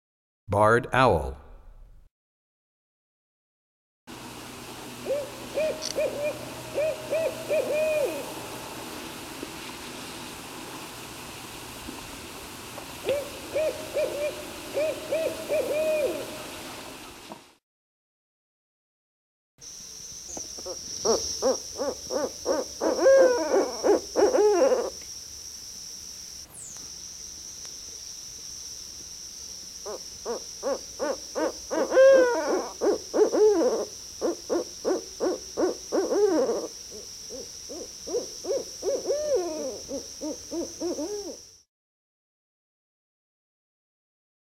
06 Barred Owl.mp3